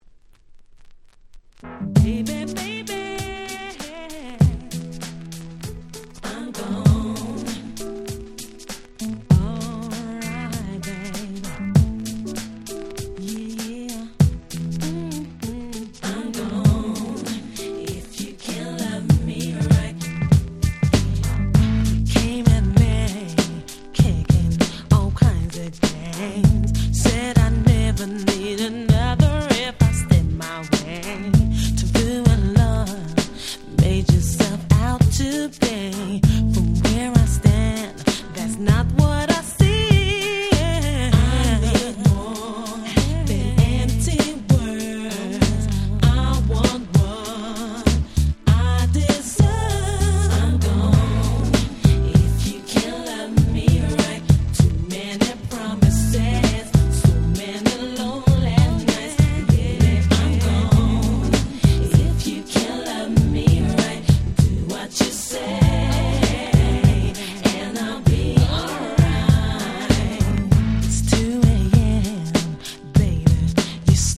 詳細が一切不明の女性R&B。
90's R&B